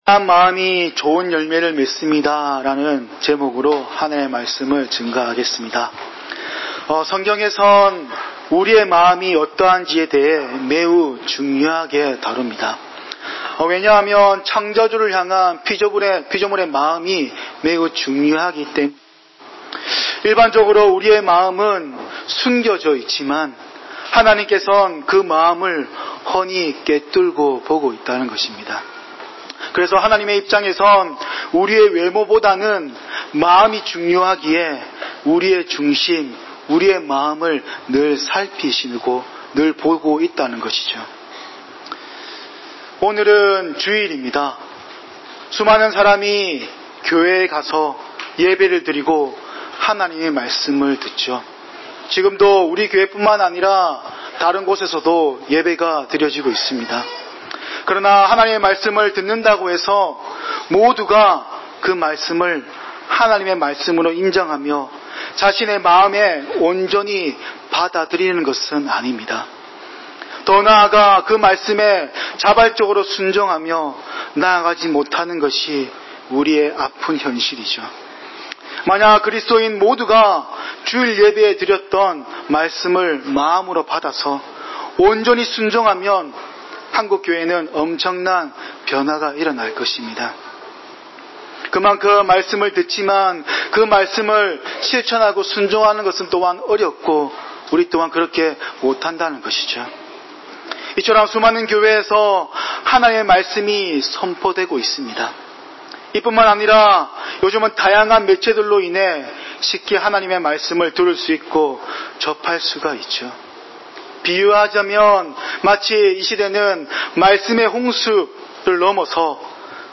예수는 그리스도시며 하나님의 아들이십니다.(마가복음 강해 9)